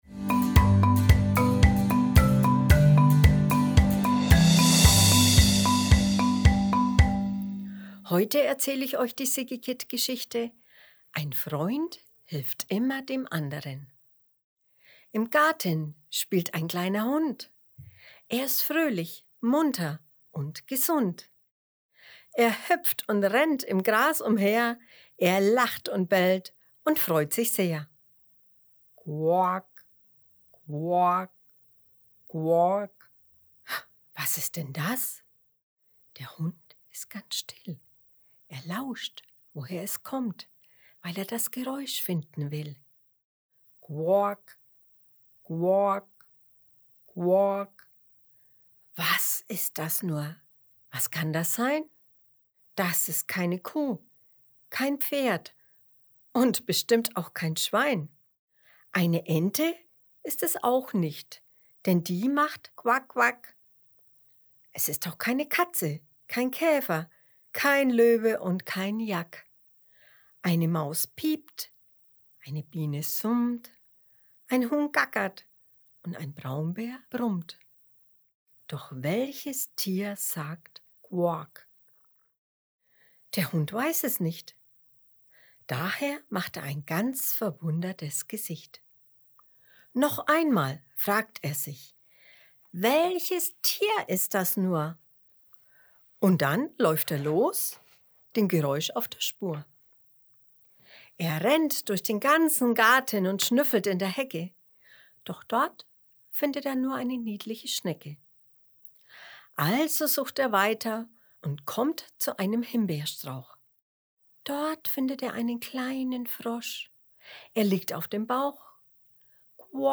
Eine liebevolle Reimgeschichte für Kinder.